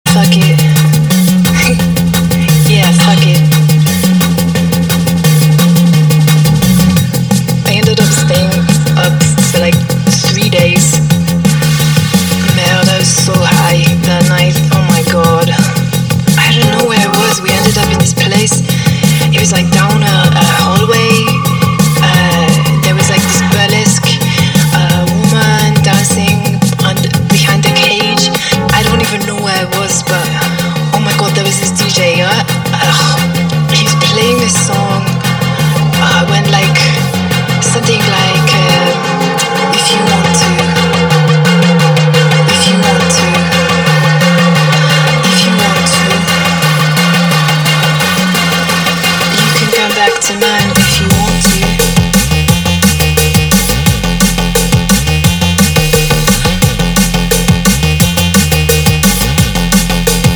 • Качество: 320, Stereo
remix
женский голос
Electronic
EDM
drum&bass
breakbeat
цикличные
Брейкбитный рингтон с приятным женским голосом